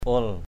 /u̯əl/ 1.